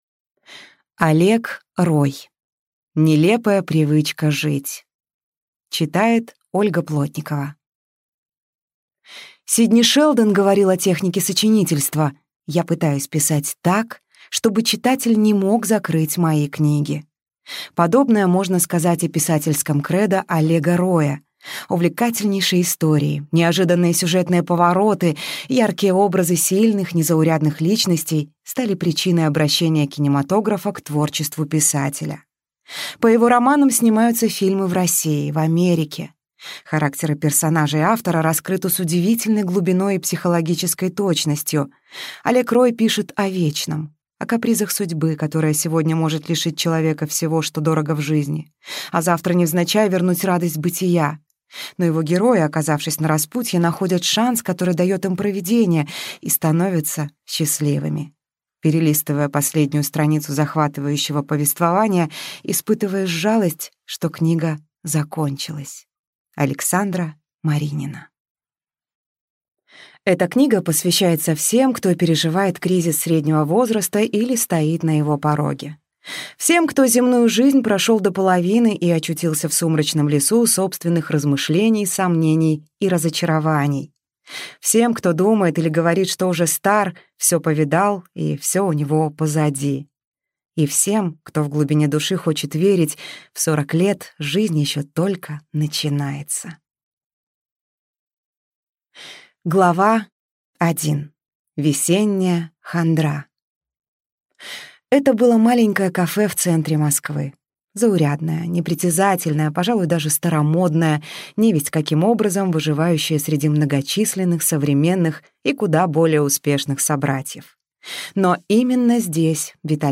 Аудиокнига Нелепая привычка жить | Библиотека аудиокниг